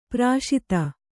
♪ prāśita